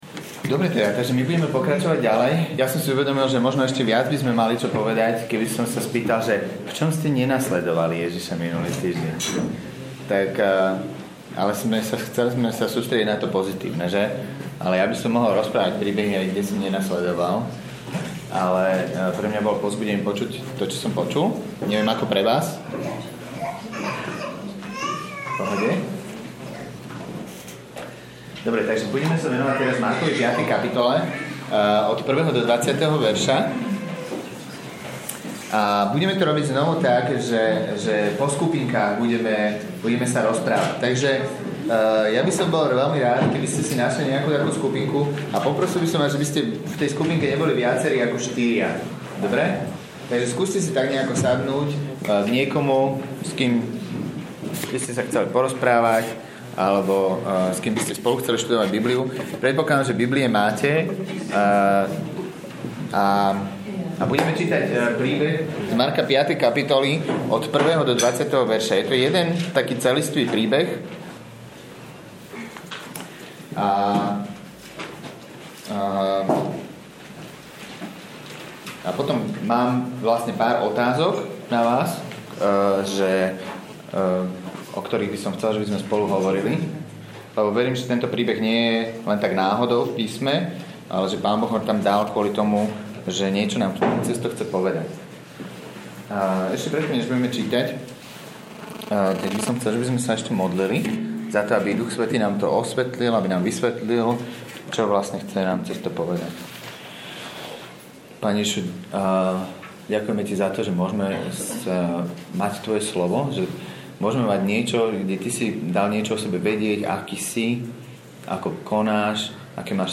Nahrávka kázne Kresťanského centra Nový začiatok z 3. apríla 2011